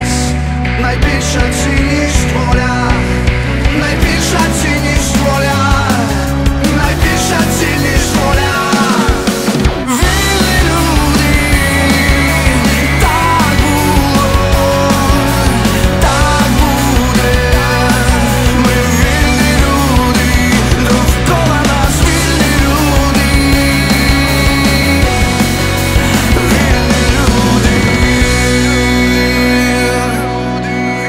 мужской голос
громкие